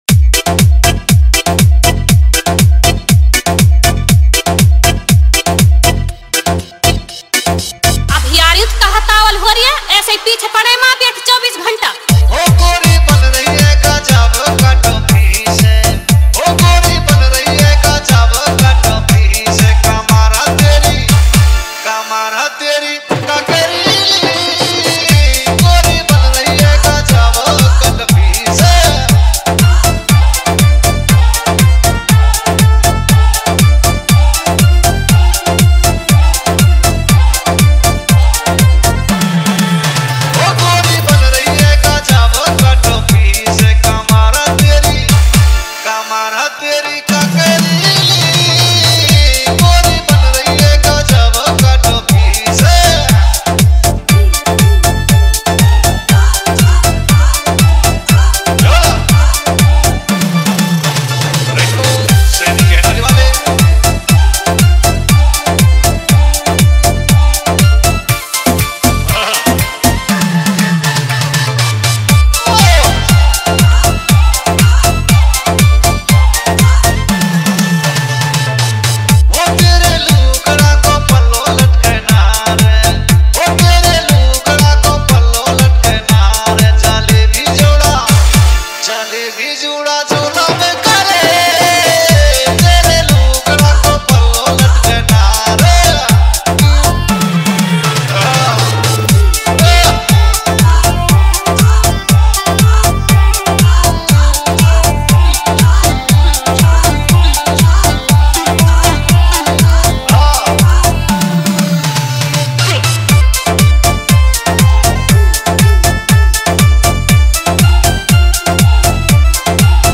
DJ Remix ,